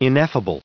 429_ineffable.ogg